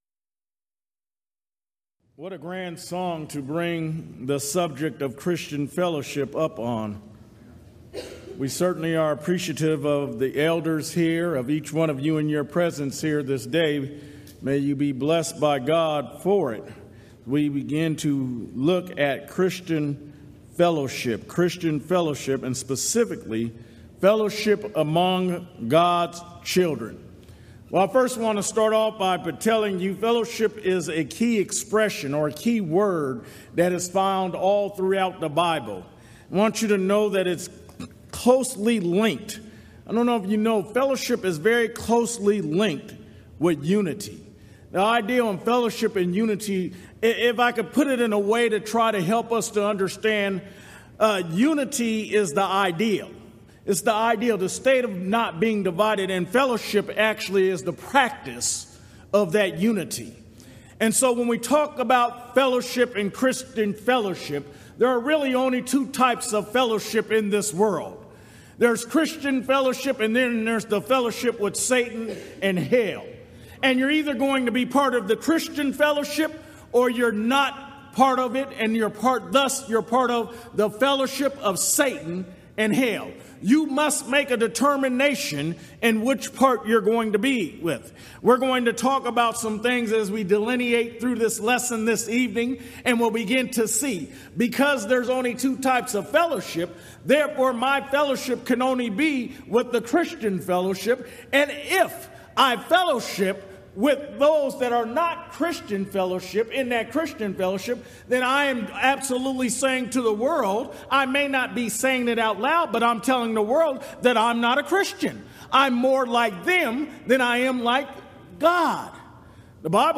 Event: 24th Annual Gulf Coast Lectures
lecture